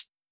Closed Hats
Hi-Hat (Lightning Fire Magic Prayer).wav